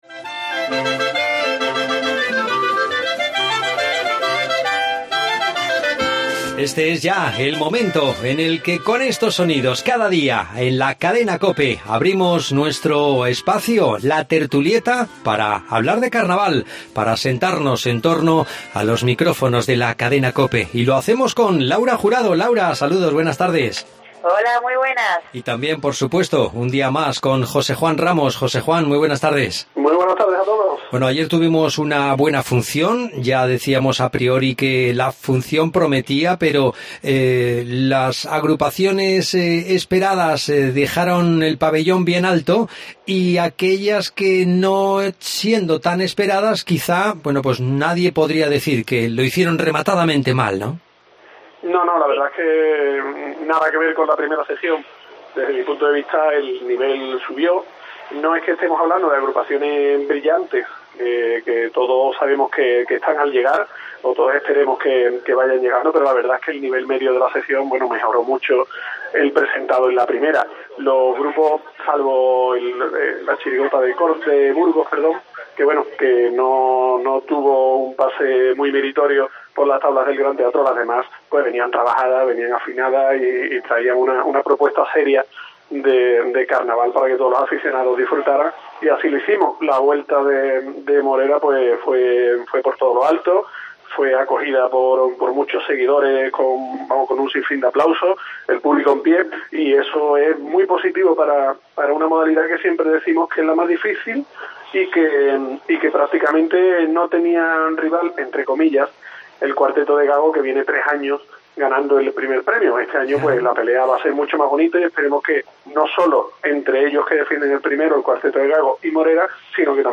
Tangos Y pasodobles